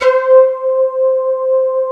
74 SYN FLT-R.wav